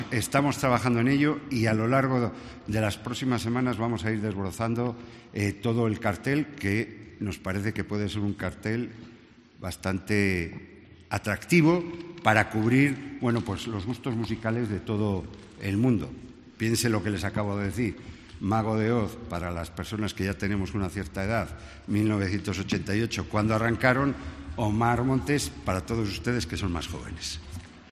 El alcalde de Valladolid, Jesús Julio Carnero, anuncia los primeros artistas del cartel de Feria